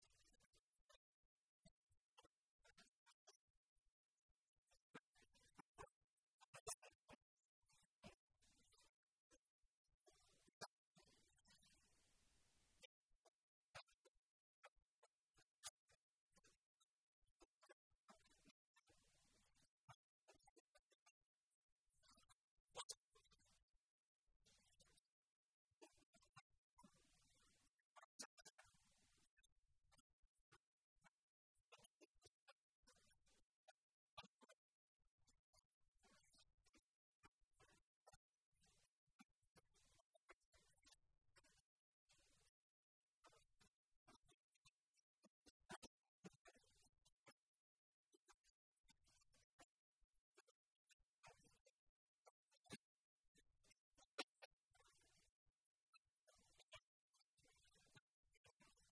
Delegado de la Junta en Guadalajara sobre el compromiso del Gobierno regional con la igualdad y contra la violencia de género